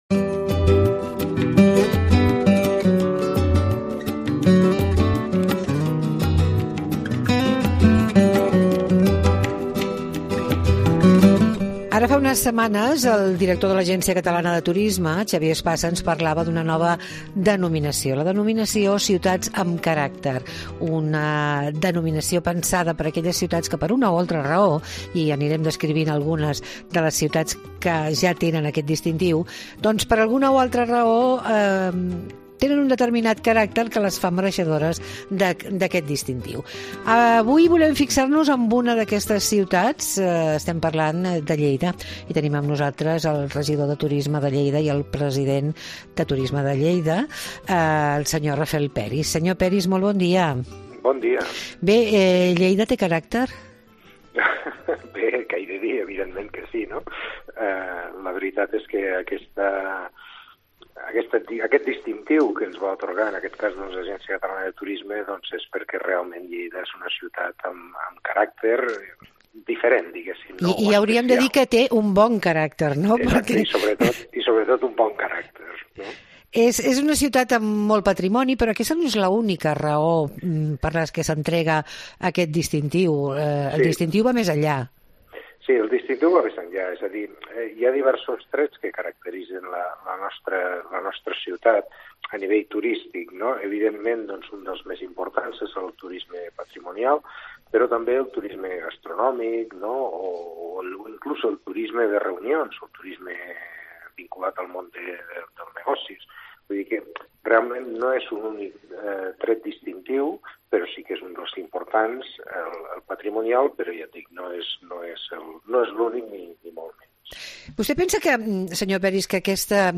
AUDIO: L'Agència Catalana de Turisme ens recomana conèixer “ciutats amb caràcter”. Parlem amb Rafel Peris, regidor i president de Turisme de Lleida.